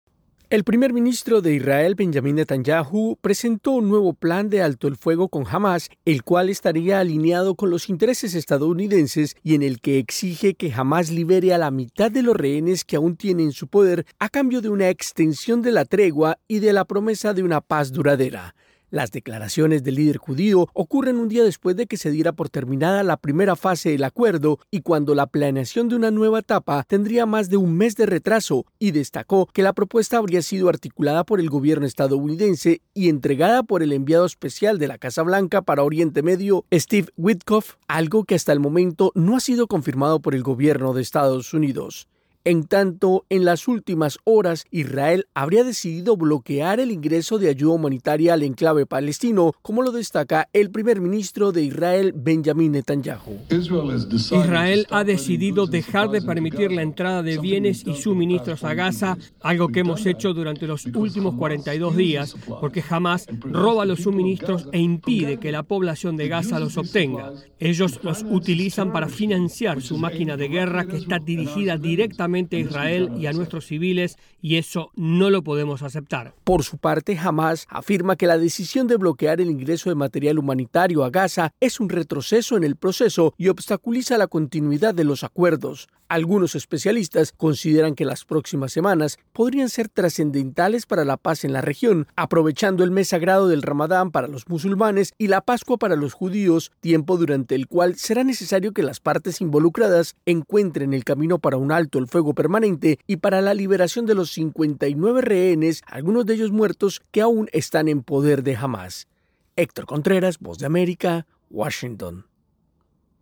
desde la Voz de América en Washington, DC.